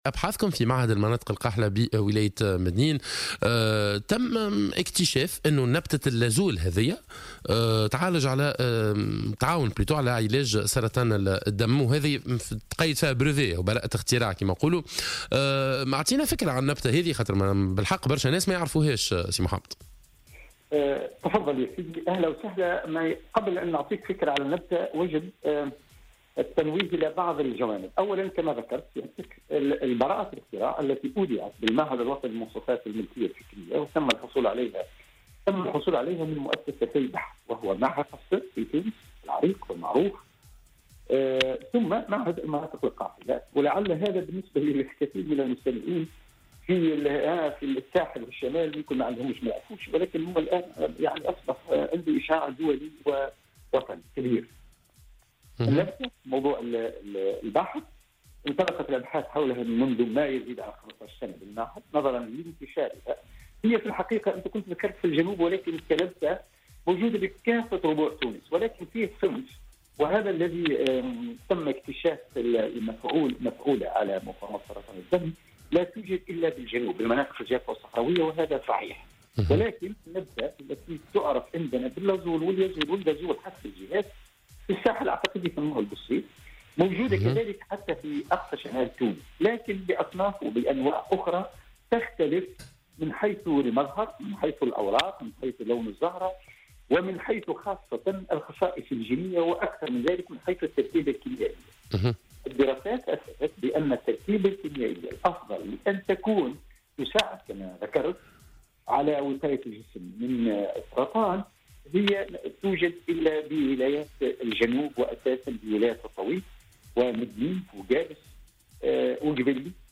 Dans une déclaration accordée à Jawhara FM ce mercredi matin